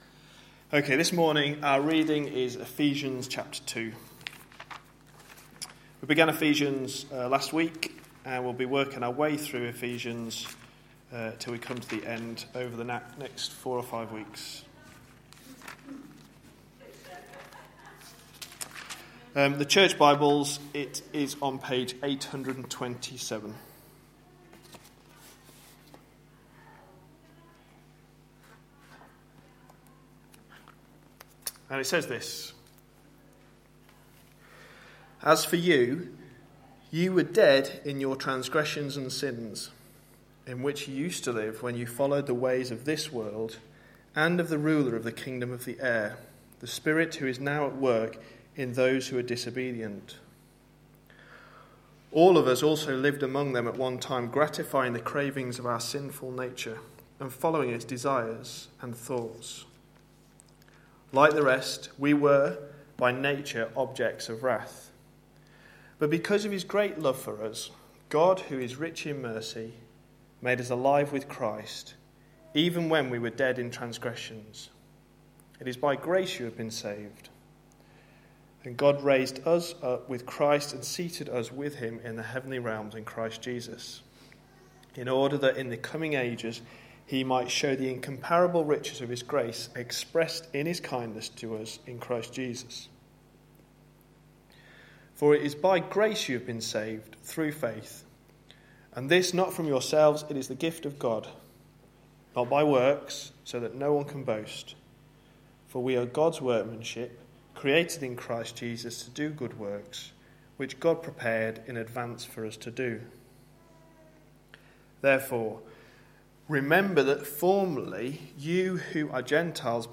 A sermon preached on 2nd June, 2013, as part of our Ephesians series.